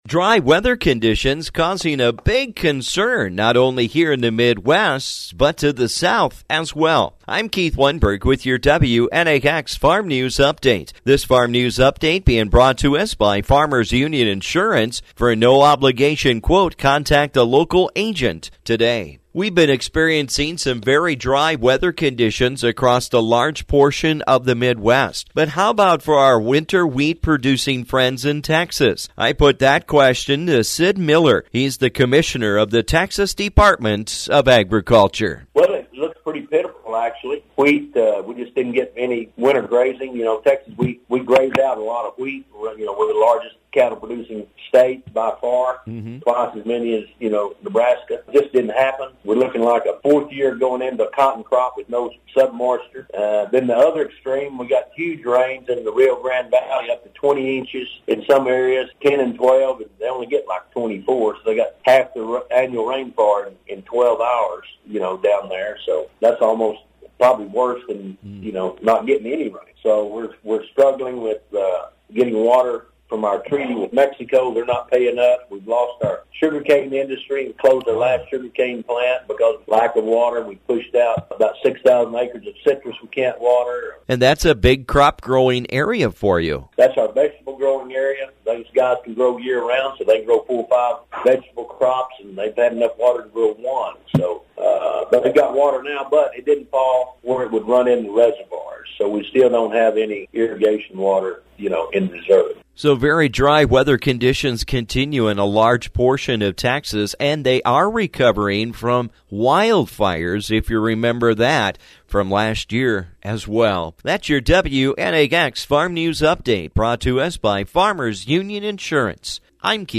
How’s it going for our Winter Wheat producing friends in Texas? I put that question to their commissioner of Agriculture, Sid Miller.